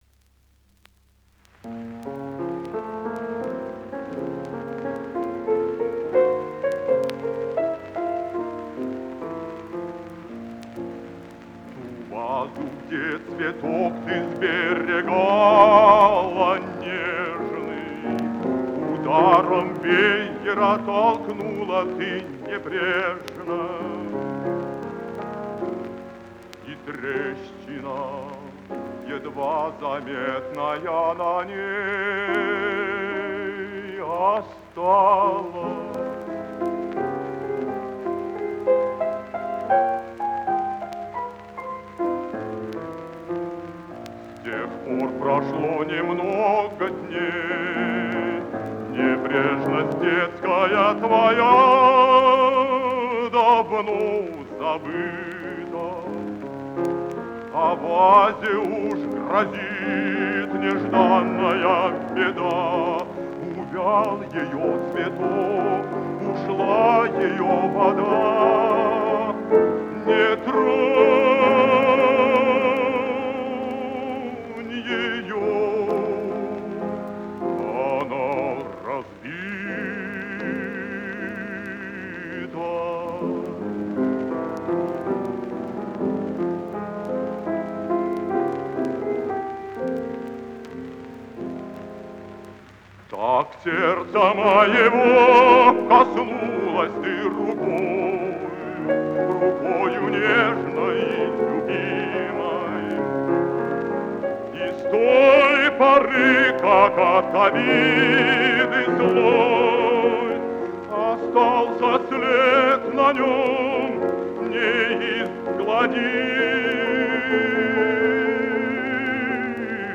«Разбитая ваза». Исполняет В. И. Касторский. Партия фортепиано